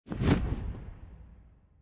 Minecraft Version Minecraft Version snapshot Latest Release | Latest Snapshot snapshot / assets / minecraft / sounds / mob / phantom / flap6.ogg Compare With Compare With Latest Release | Latest Snapshot
flap6.ogg